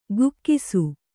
♪ gukkisu